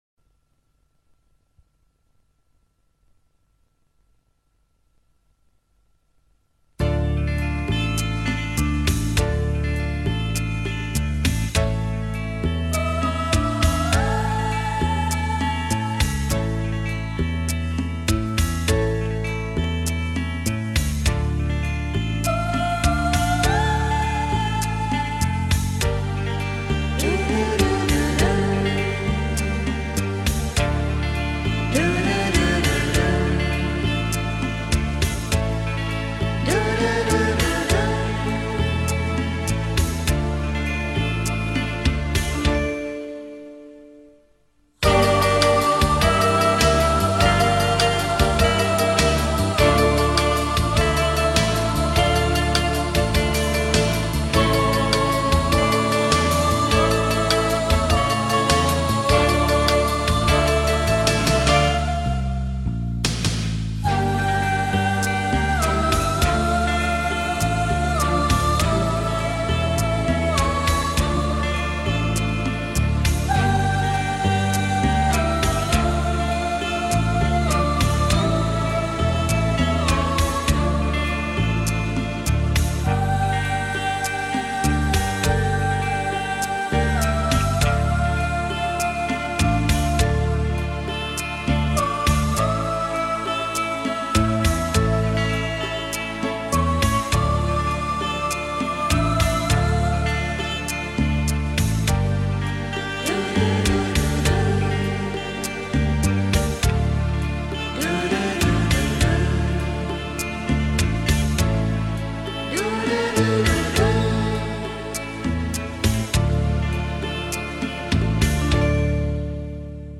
Отличная минусовка!